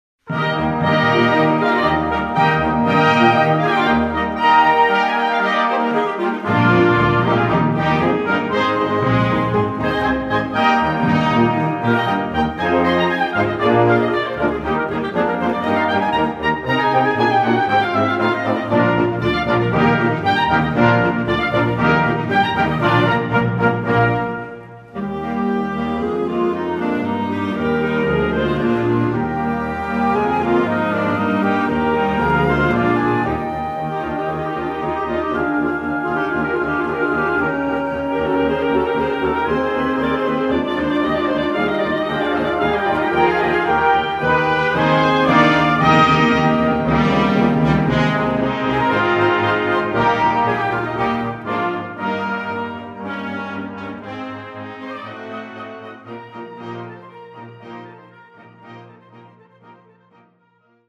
Gattung: Horn in F Solo
Besetzung: Blasorchester